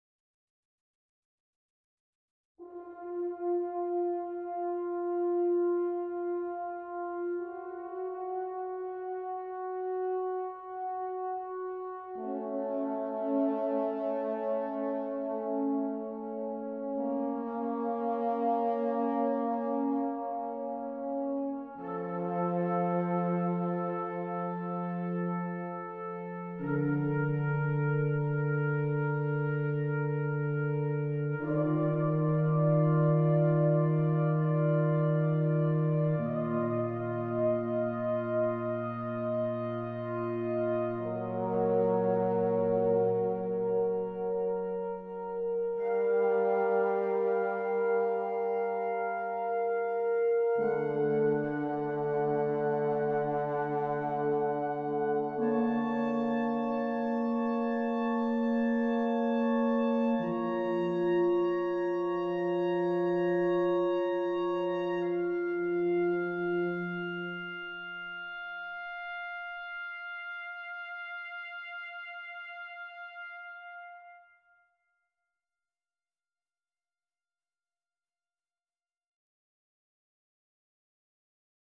Music from the original motion picture soundtrack
Recorded at Stair 7 Studios
drums, percussion
trumpet
trombones